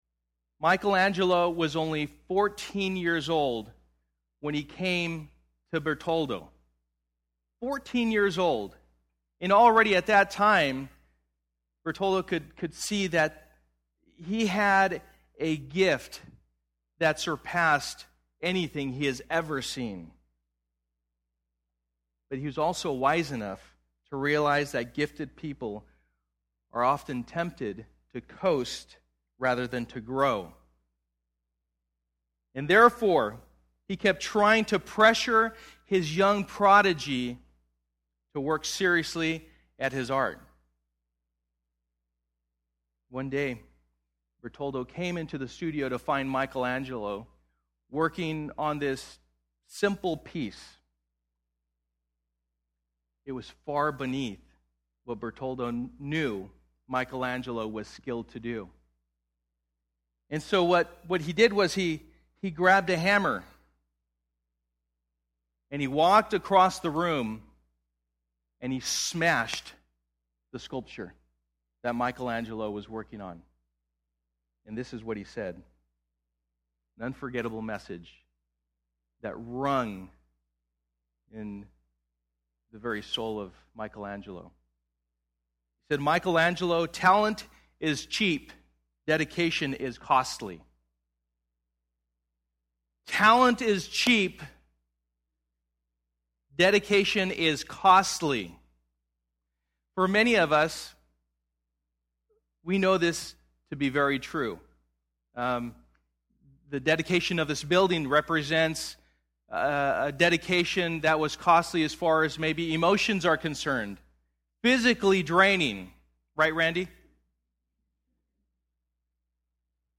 Dedication Service